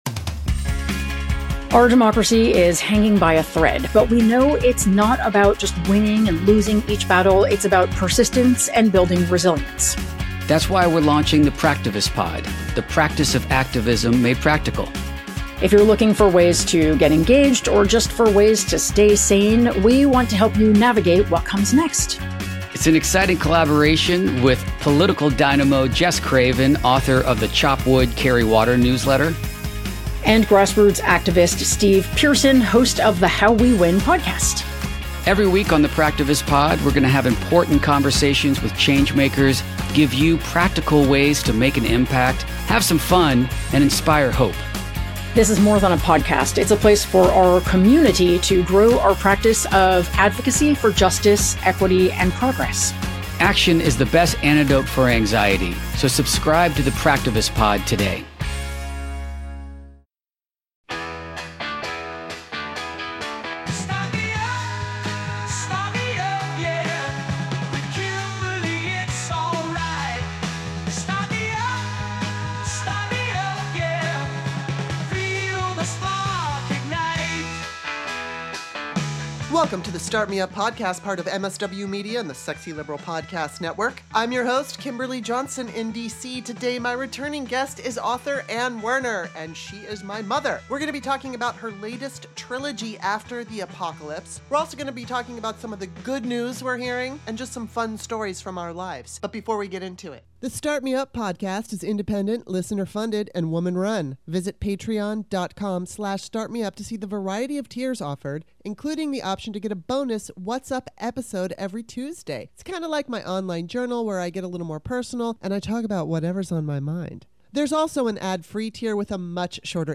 I'm not always politically correct and I'm a huge fan of loose talk and salty language. Sarcasm is guaranteed and political correctness will be at a minimum. This podcast is for anyone who wants to listen to compelling conversations with a variety of guests about current events and controversial topics.